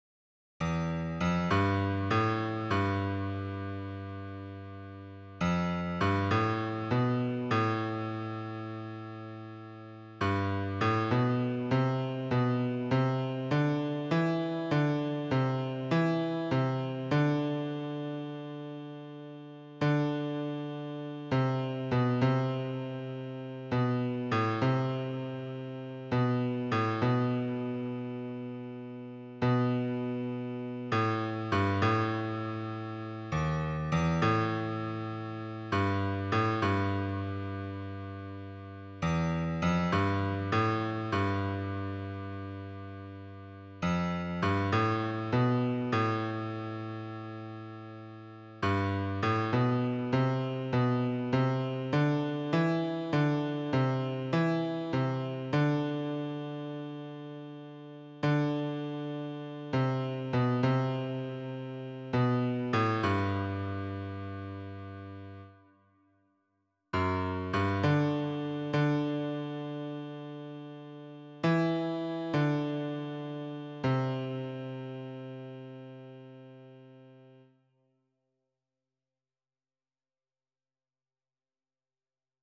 esse_seu_olhar_-_baixo_grave[50287].mp3